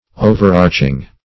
Search Result for " overarching" : The Collaborative International Dictionary of English v.0.48: overarching \o`ver*arch"ing\, a. 1.